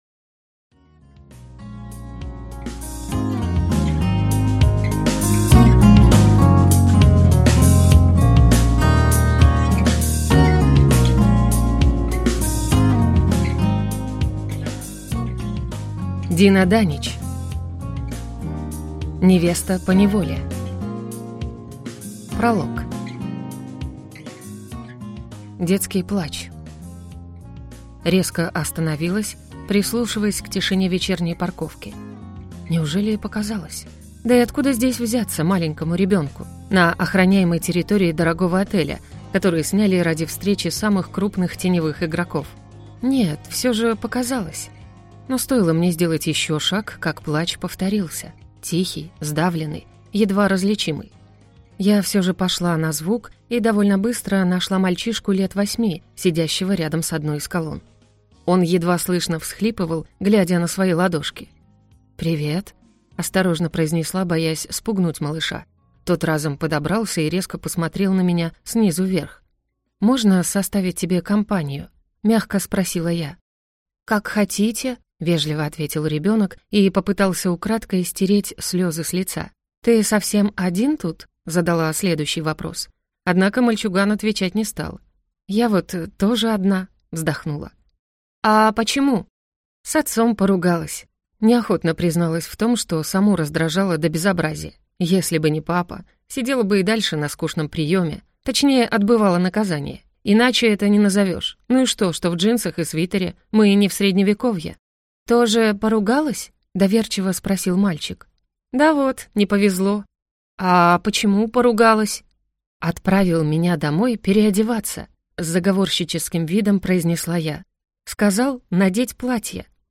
Аудиокнига Невеста поневоле | Библиотека аудиокниг